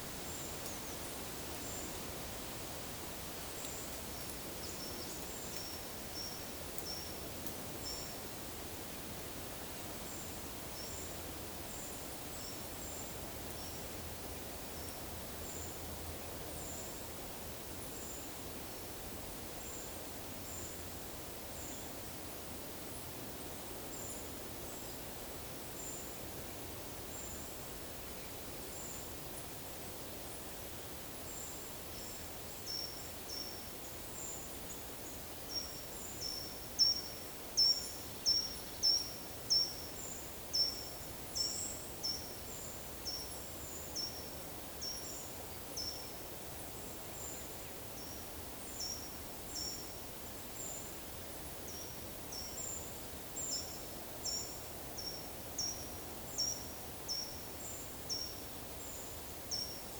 Monitor PAM
Certhia brachydactyla
Certhia familiaris
Turdus iliacus